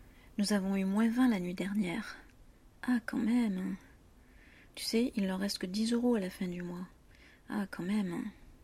la surprise ou l’admiration ou le fait qu’on est impressionné: